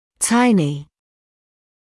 [‘taɪnɪ][‘тайни]крошечный; маленький